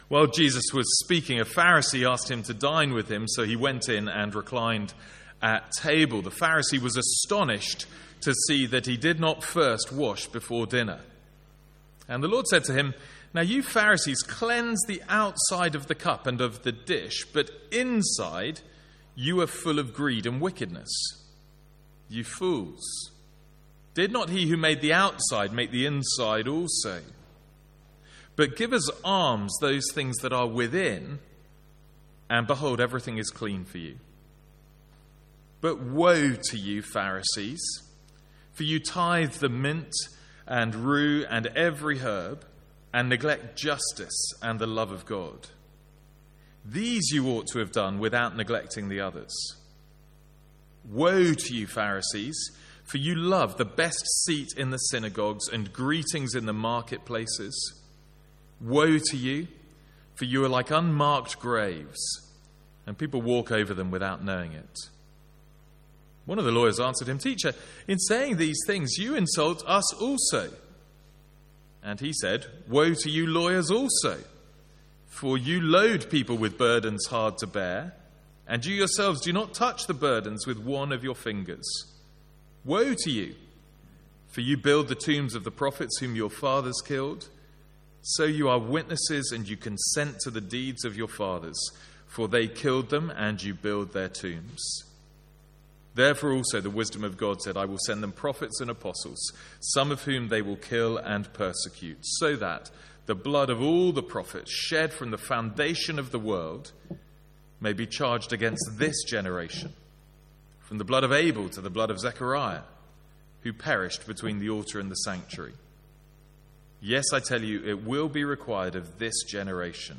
Sermons | St Andrews Free Church
From the evening series in Luke.